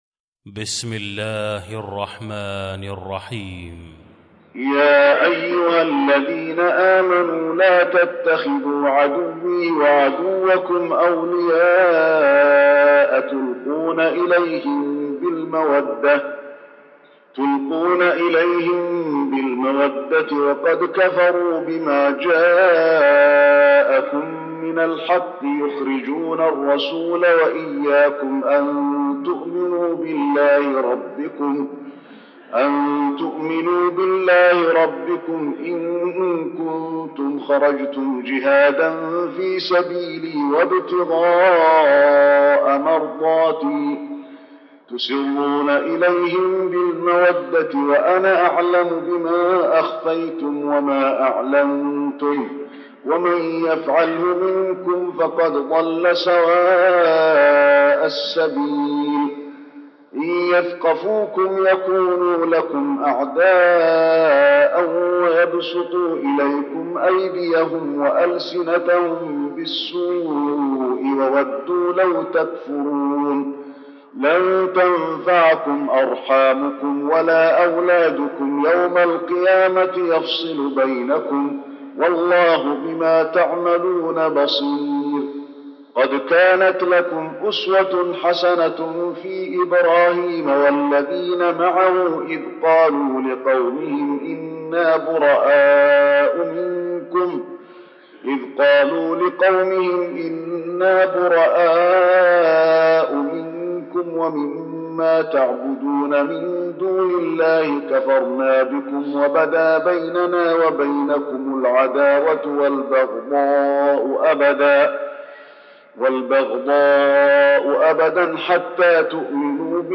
المكان: المسجد النبوي الممتحنة